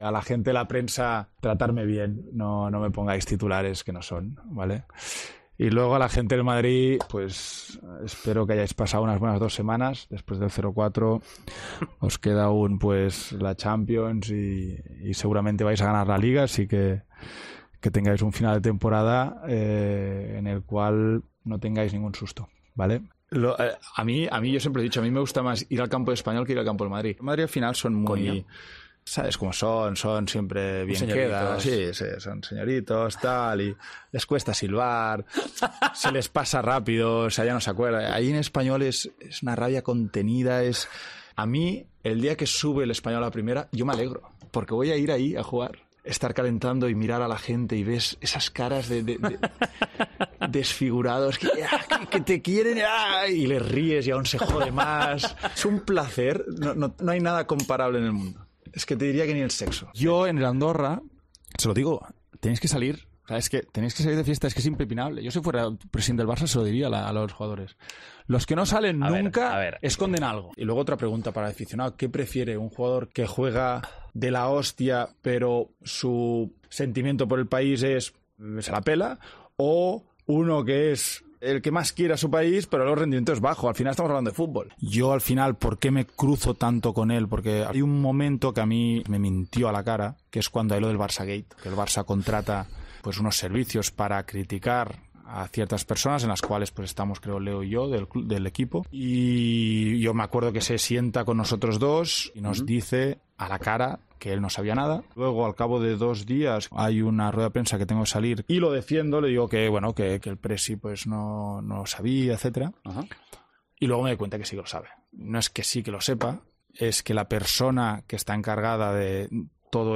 Los mejores momentos de la entrevista de Piqué con Jordi Wild, en El Partidazo de COPE